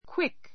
quick A2 kwík ク ウィ ク 形容詞 ❶ すばやい, 速い, 短時間の 反対語 slow （遅 おそ い） have a quick shower [breakfast] have a quick shower [breakfast] さっとシャワーを浴びる[朝食を済ませる] Be quick about your work.